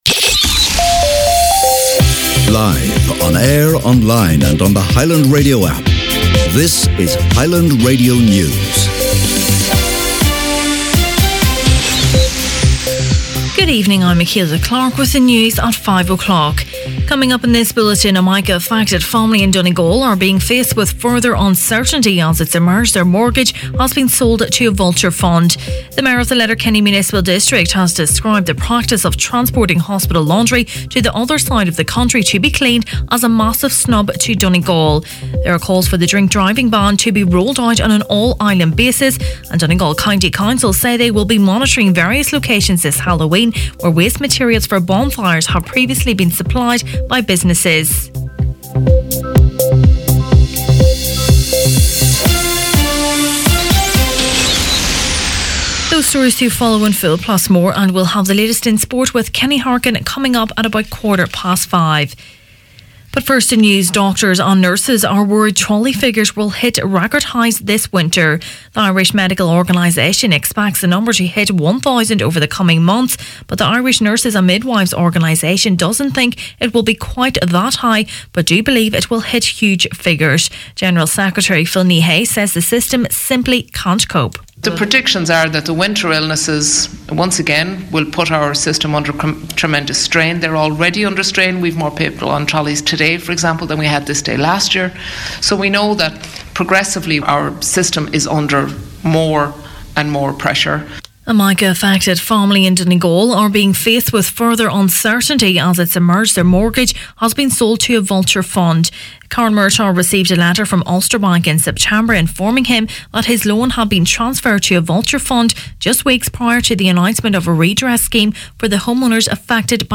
Main Evening News, Sport and Obituaries Tuesday October 30th